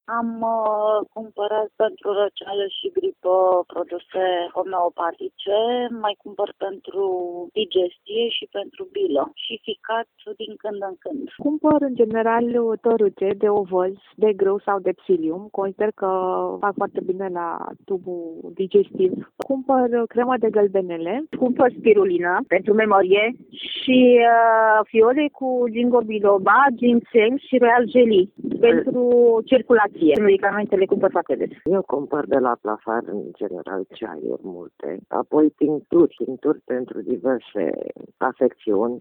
vox-plafar-6-feb.mp3